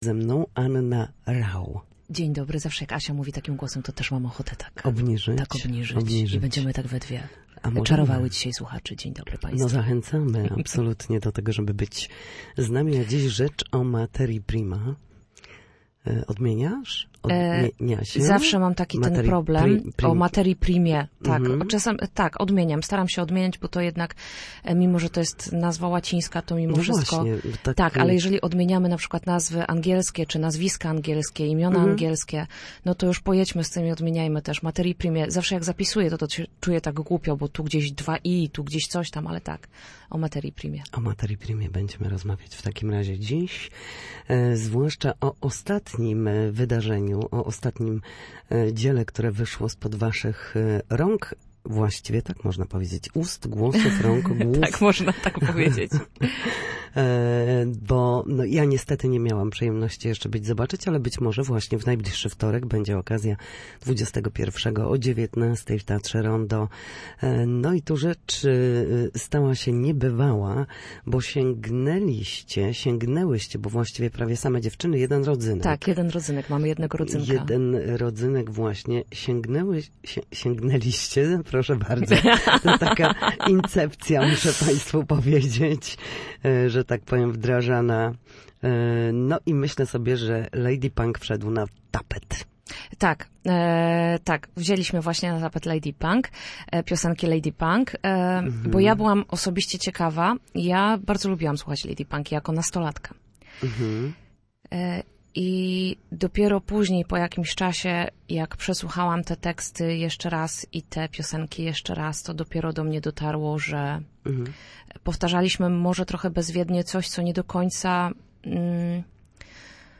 Gościem Studia Słupsk
Na naszej antenie mówiła o ostatniej produkcji Materii Primy.